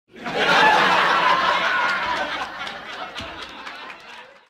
Play Funny Laugh Track - SoundBoardGuy
Play, download and share Funny Laugh Track original sound button!!!!
funny-laugh-track.mp3